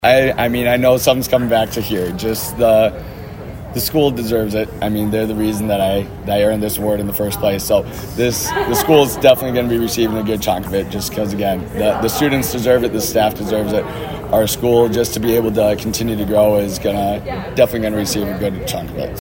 ABERDEEN, S.D.(HubCityRadio)- Students and faculty were in for a surprise of lifetime Thursday at Aberdeen Roncalli Middle & High School.